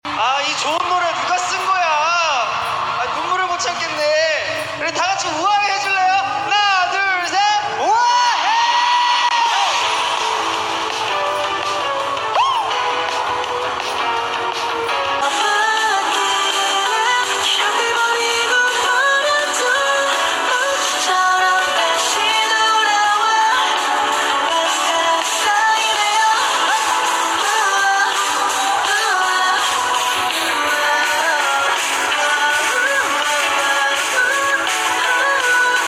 seungkwan making carats shout wooahae